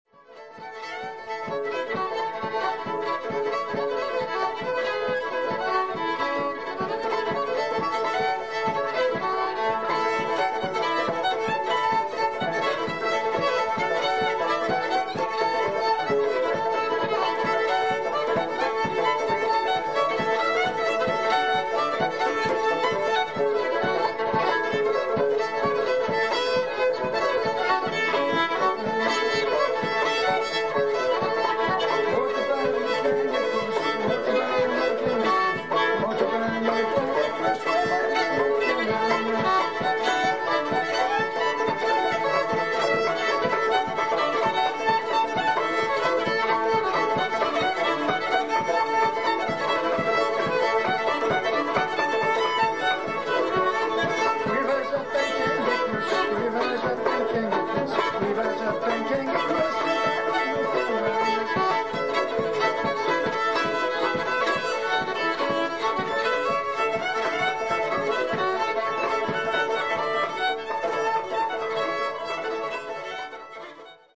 Baritone Banjo!?
Guitar
Fiddle